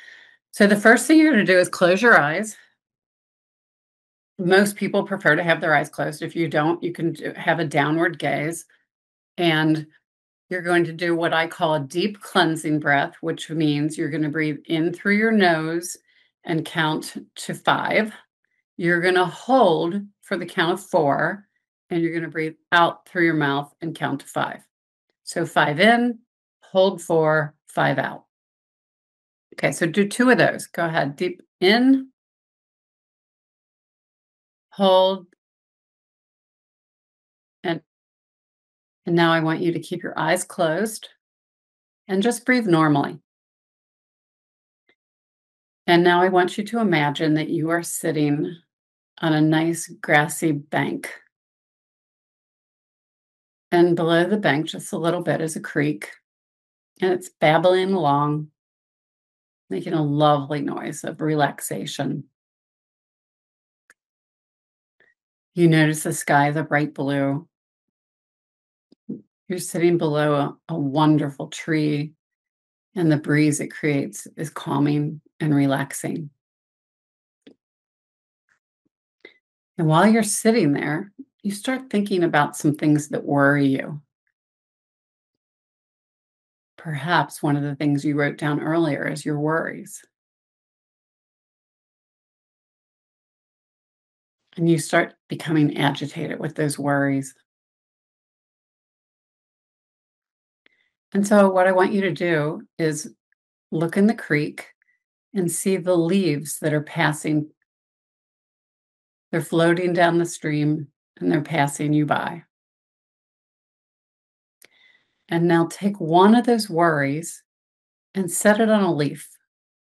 Guided Observation Audio
guidedmeditation.m4a